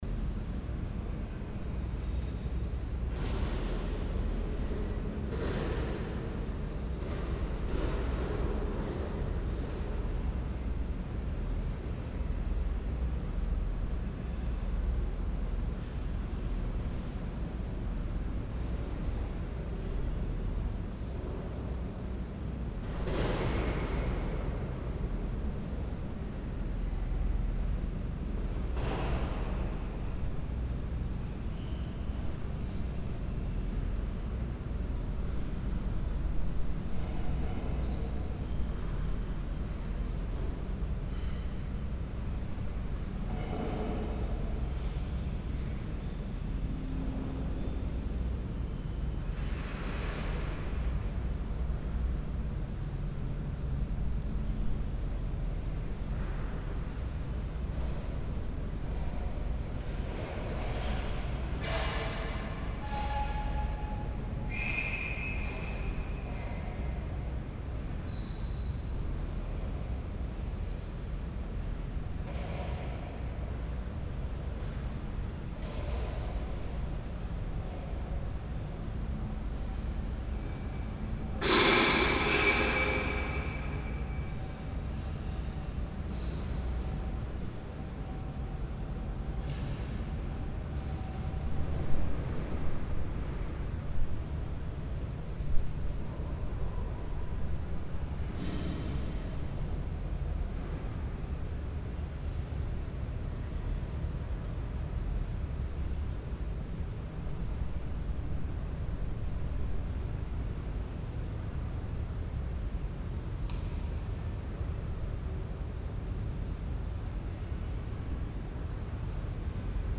improvised and contemporary music